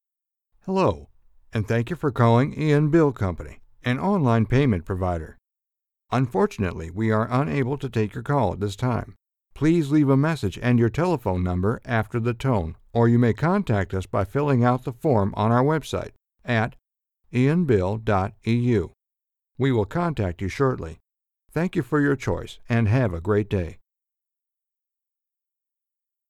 Профессиональный диктор из США
* RODE NT-1A MICROPHONE * STEINBERG WITH CI TECHNOLOGY * CUBASE AI 5 EDITING SOFTWARE * AUDACITY MIXER BACKUP SOFTWARE